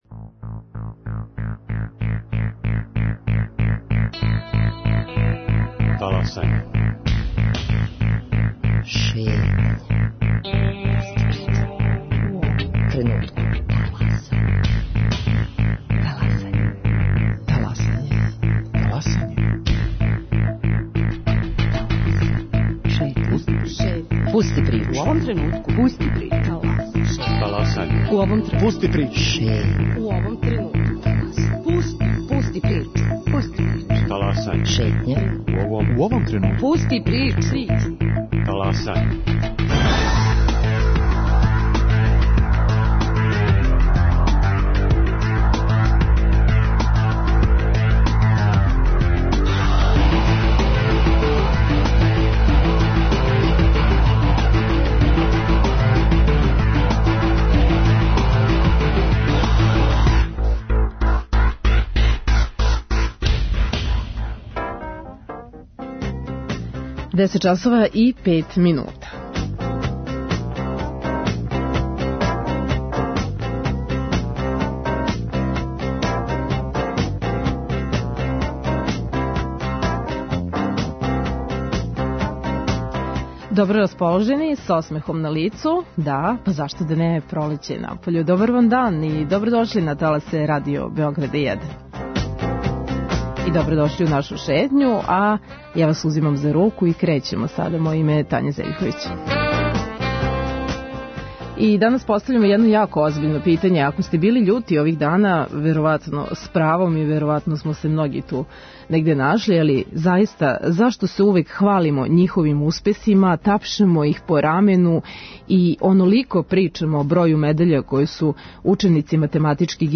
Биће гости у нашем студију